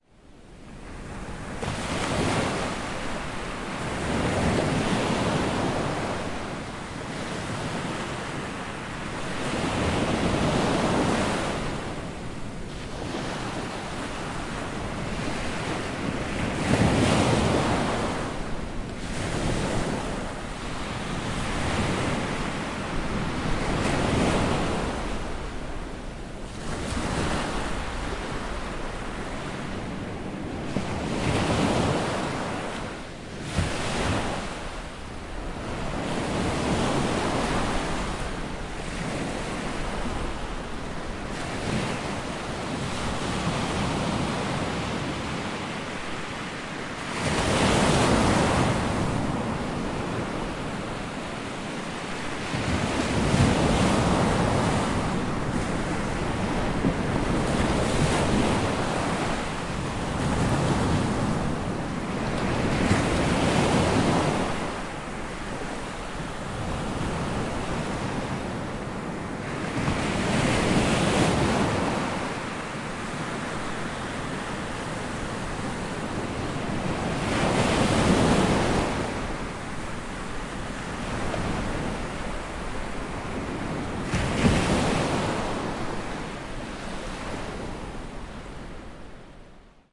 湖的声音 " 伊利湖的灯光冲浪2
描述：水轻轻地滚动并拍打伊利湖的岸边。晚上2018年春末
标签： 沙滩 海浪 海浪 海岸 海滨 性质 现场记录 环境 音场 冲浪
声道立体声